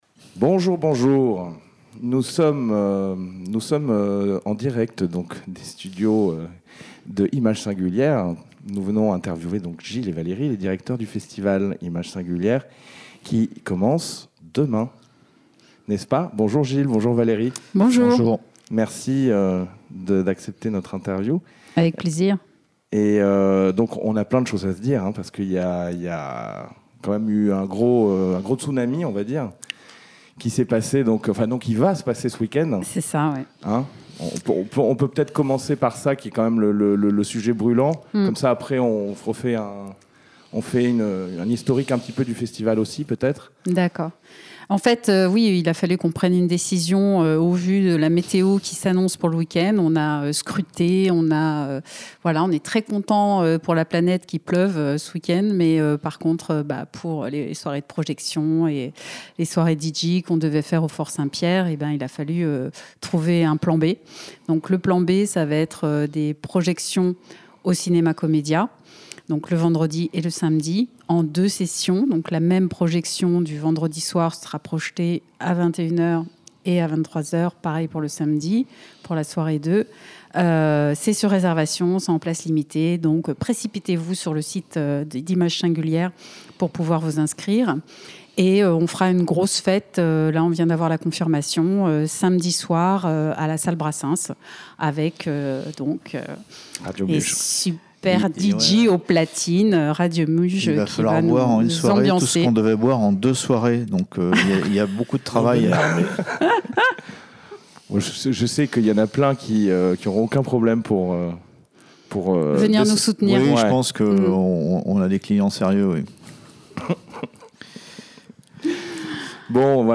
ITW réalisée & enregistrée le mercredi 17 Mai dans les bureaux de la MID (Maison de l'image documentaire)